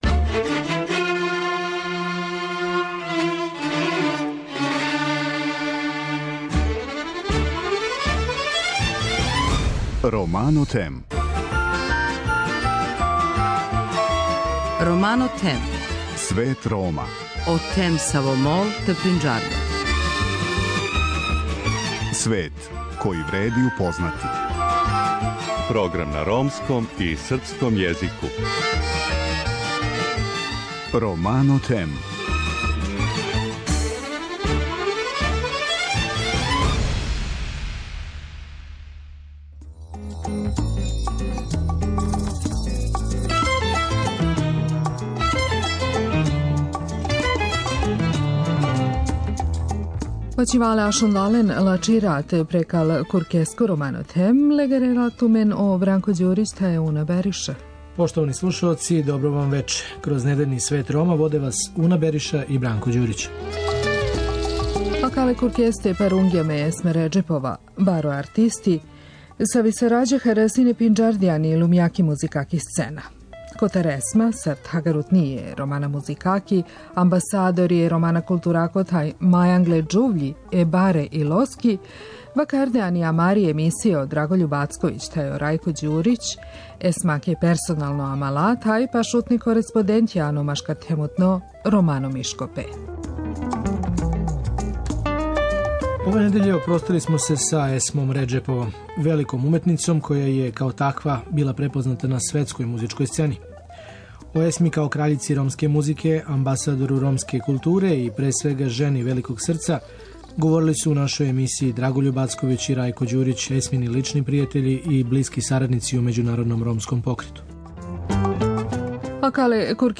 Репризно вам преносимо сведочења преживелих страдалника из Београда.